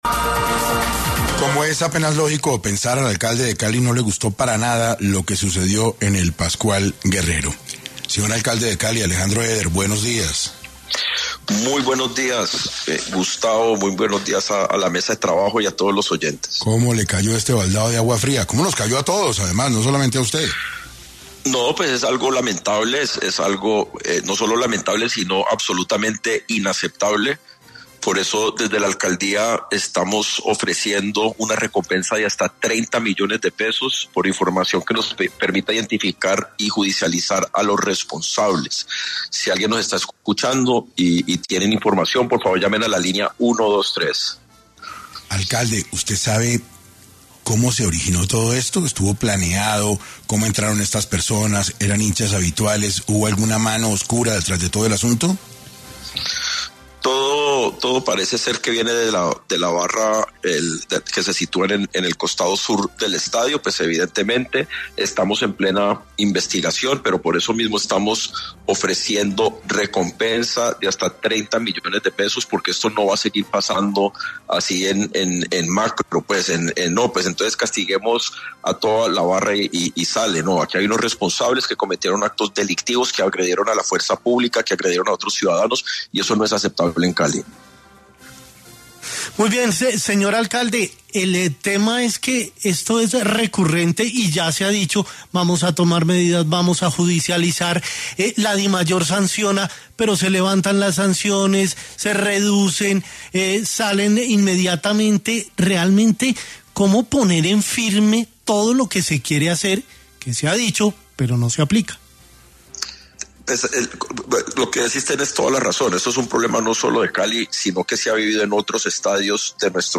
El alcalde de Cali recalcó en el noticiero ‘6AM’ que ofrecen una recompensa de 30 millones por información sobre los responsables de los desmanes en el Pascual Guerrero.